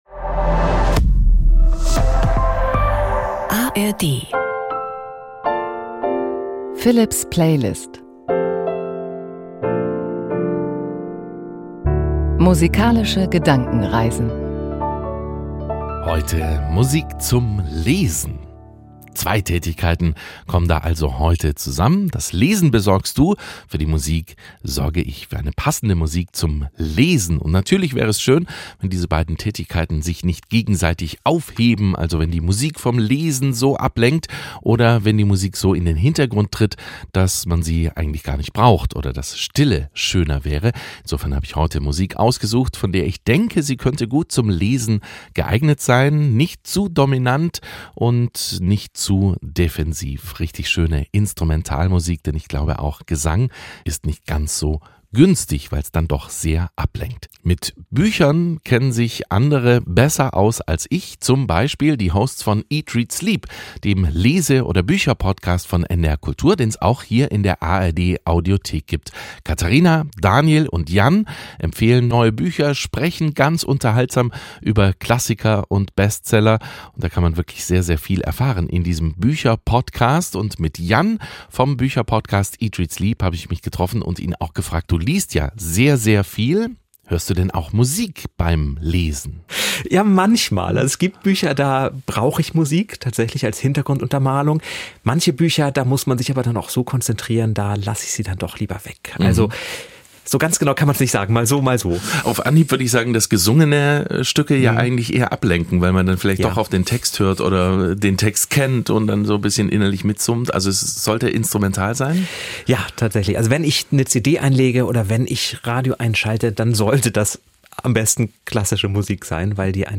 Sanfte Streicher und tragende Piano-Klänge: